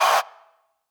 metro tm siz vox.wav